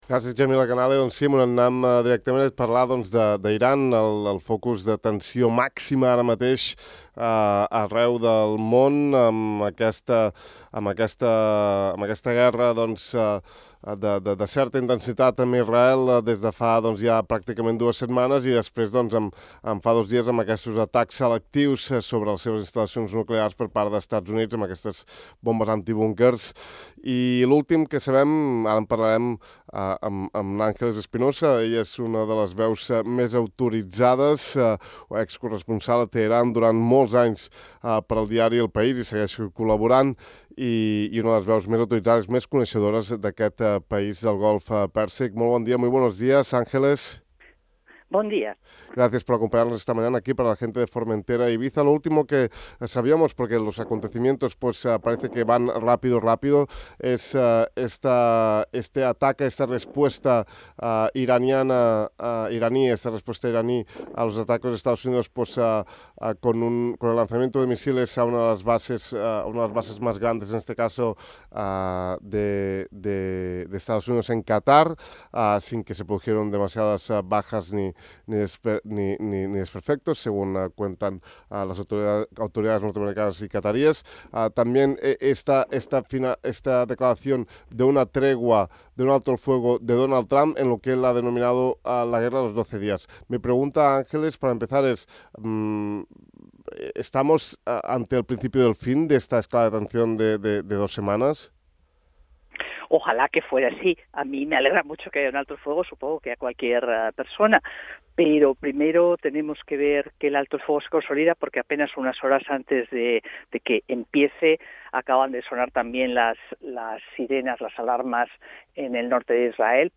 Parlem amb Ángeles Espinosa, excorresponsal de El País a Teheran sobre l’atac d’EUA a l’Iran i la situació bèl·lica actual entre aquest darrer país i Israel: